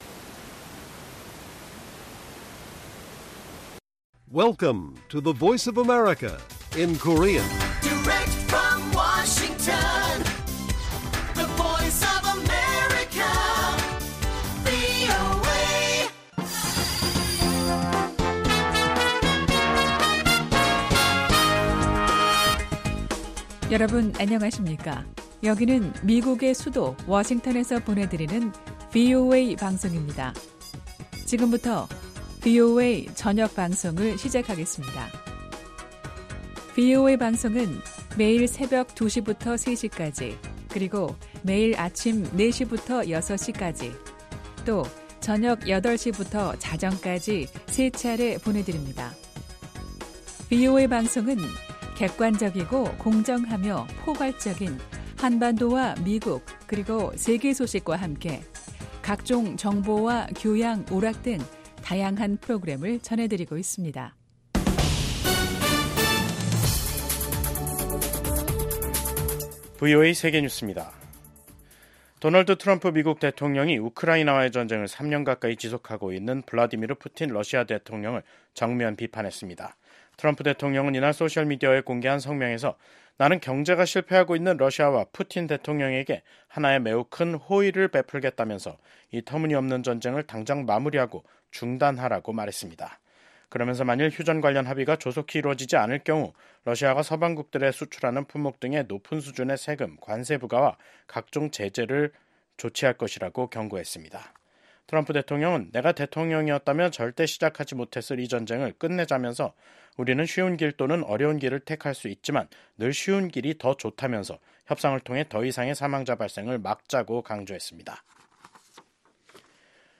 VOA 한국어 간판 뉴스 프로그램 '뉴스 투데이', 2025년 1월 23일 1부 방송입니다. 미국 공화당 의원들은 도널드 트럼프 대통령의 두 번째 임기를 환영하며, 북한과 중국, 러시아, 이란 등 독재국가들에 대한 강경 대응을 예고했습니다. 마르코 루비오 미국 국무장관은 조태열 한국 외교부 장관은 도널드 트럼프 대통령 취임 사흘날 첫 전화 통화를 하고 북핵 문제에 대해 긴밀한 공조를 유지하기로 했습니다.